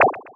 alien_hit_01.wav